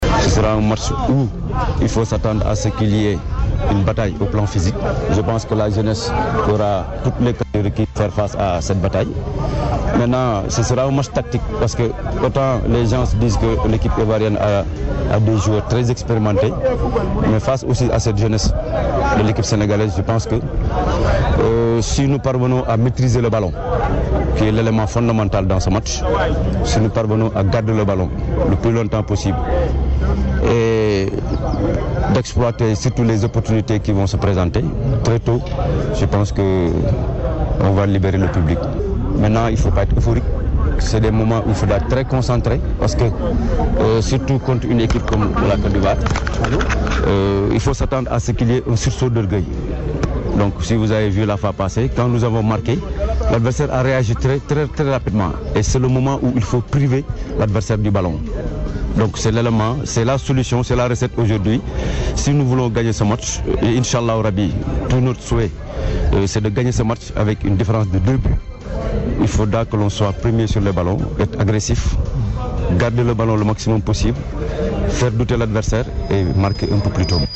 Il est interrogé par nos confrères de la Rfm.